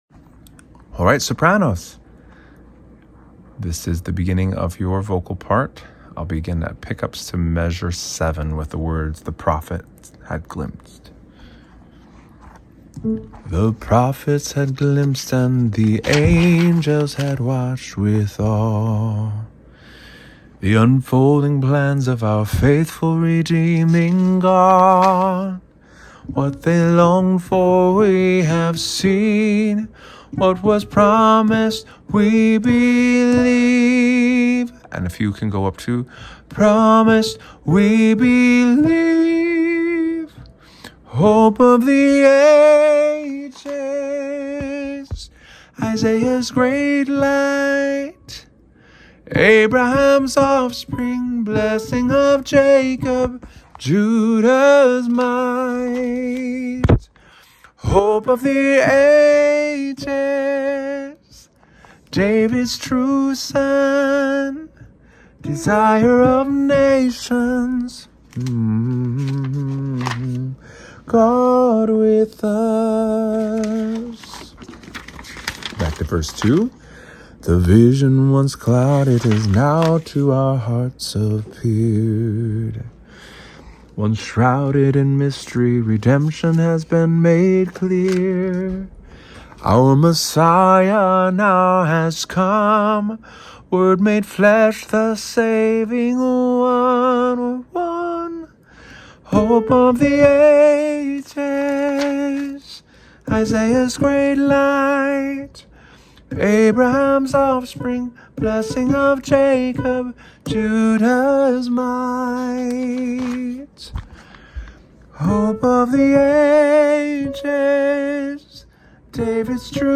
Rehearsal Tracks:
hope-of-the-ages-soprano-sing-along.mp3